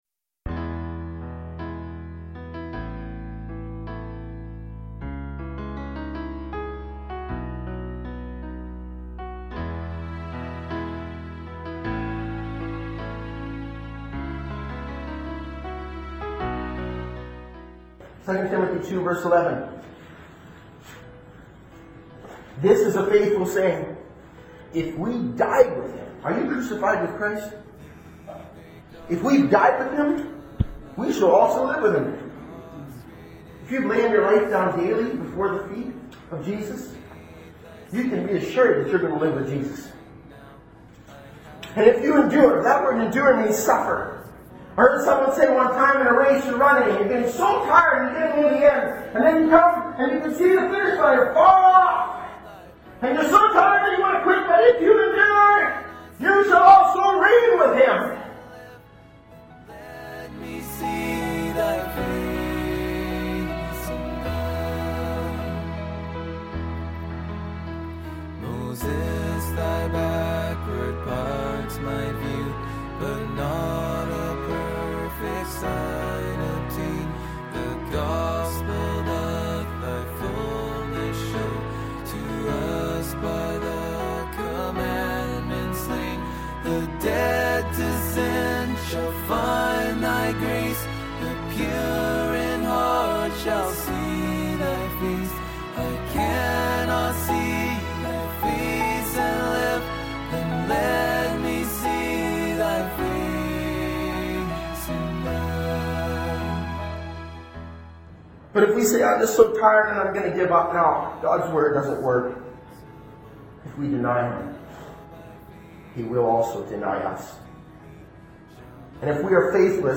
In this sermon, the preacher emphasizes the importance of believing in the freedom that comes through Jesus.